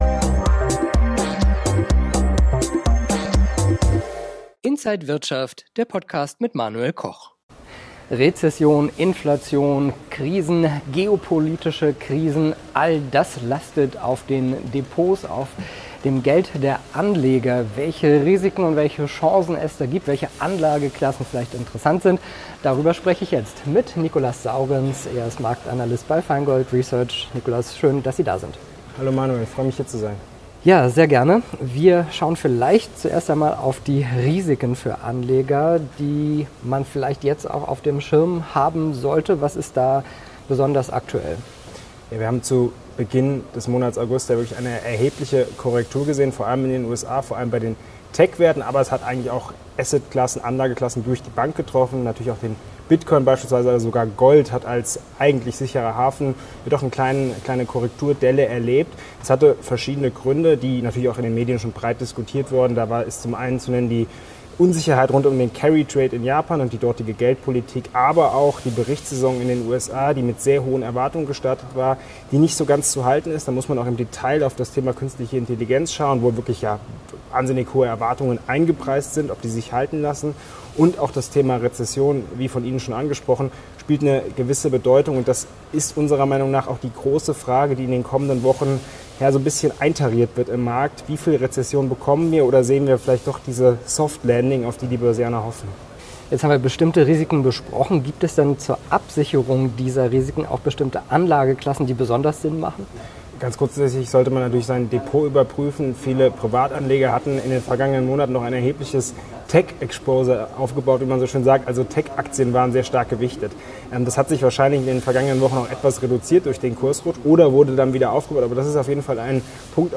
an der Frankfurter Börse